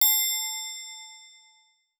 Long Bell Notification.wav